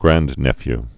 (grăndnĕfy, grăn-)